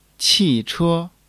qi4--che1.mp3